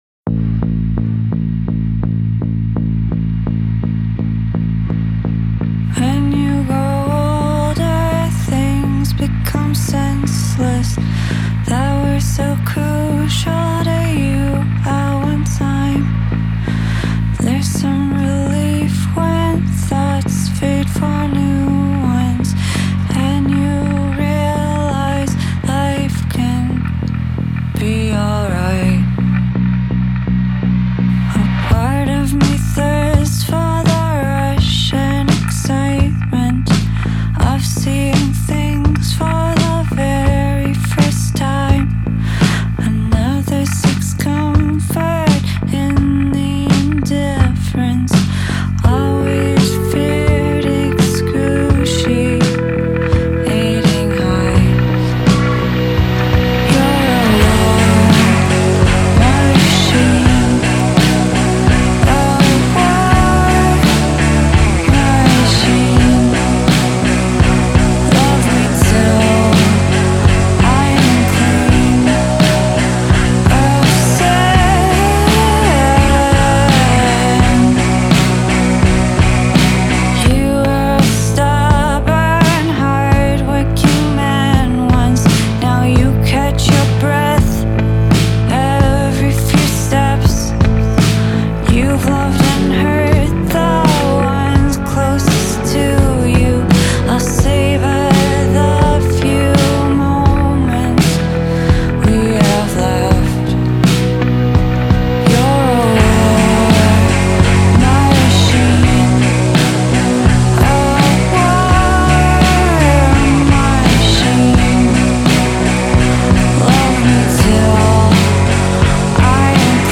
Трек размещён в разделе Зарубежная музыка / Альтернатива.